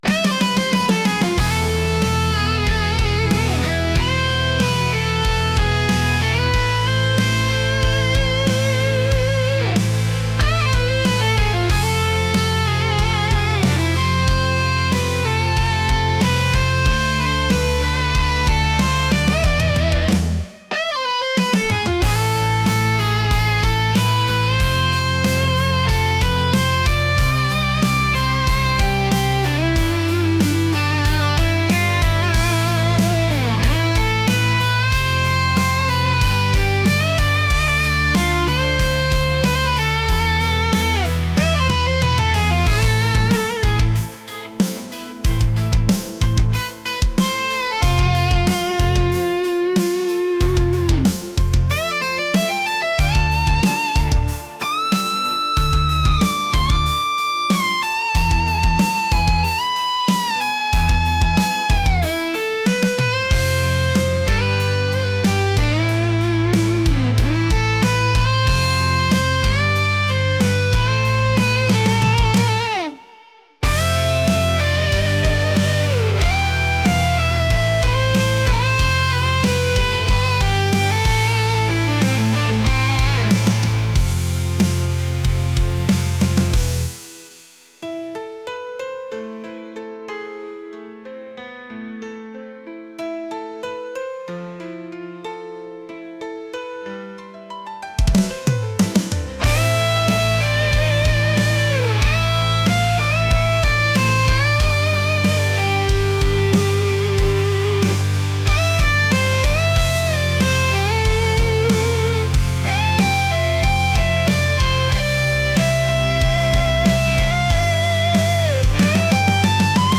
heavy | rock